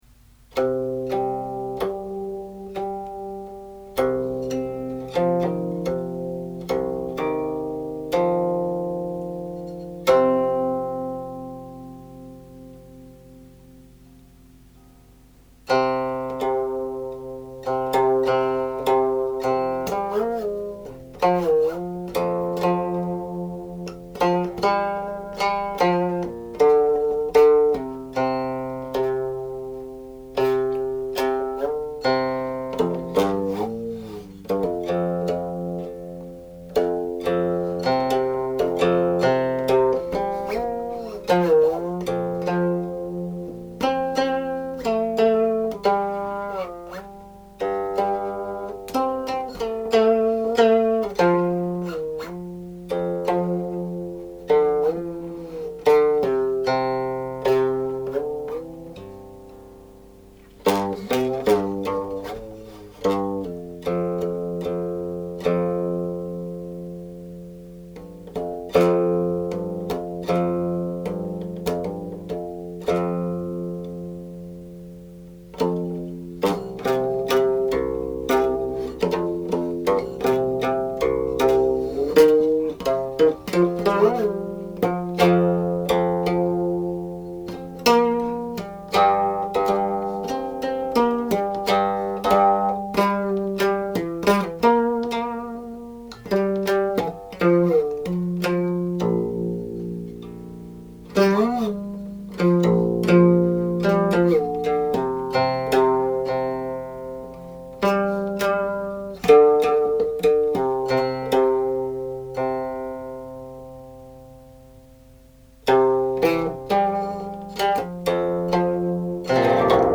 Thus, after their opening phrases Section 5 largely repeats Section 1, but one octave higher; then the opening of Section 6 repeats the opening of Section 2, after which most of the rest of Section 6 repeats Section 4, in both cases again one octave higher.11
Eight Sections, untitled (but added below from the Longhu Qinpu version15)